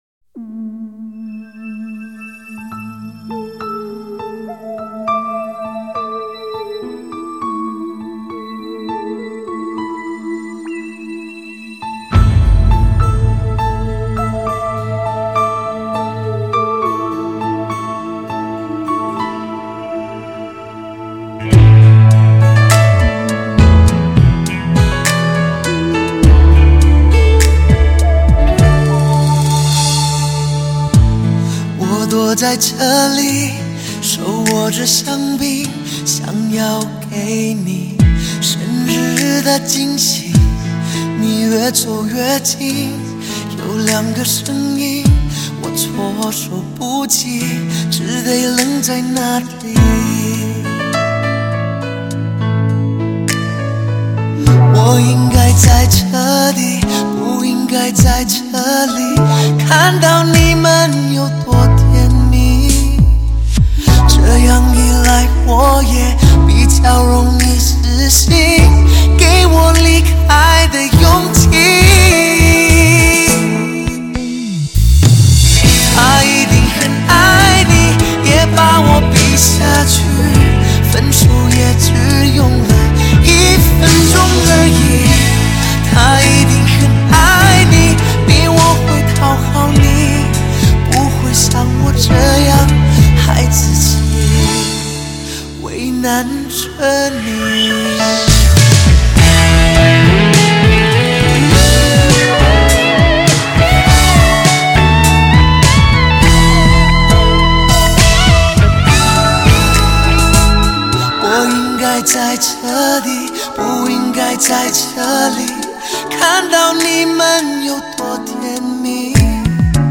新世纪纯新未进化完全真男人的声音